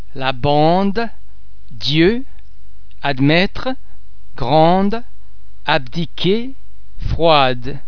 Please be mindful of the fact that all the French sounds are produced with greater facial, throat and other phonatory muscle tension than any English sound.
The French [d] and [dd] are normally pronounced a single [d] sound as in the English words addres, addition, dad, drive etc.